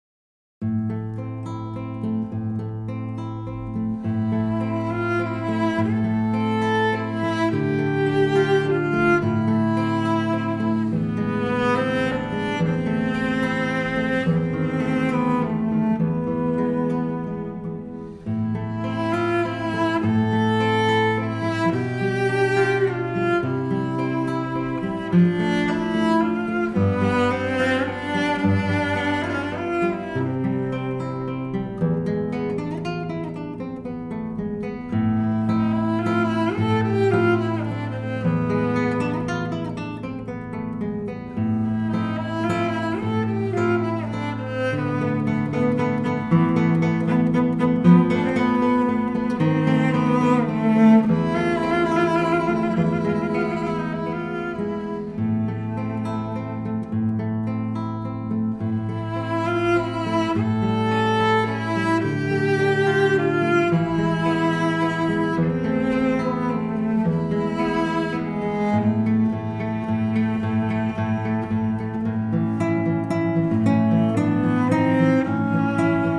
★ 兩位國際知名的演奏家合作演出難得一見的大提琴與吉他曲目，讓人一聽難忘！
★ 優異的錄音水準、浪漫悅耳的旋律，充分表現出大提琴之柔與吉他之美！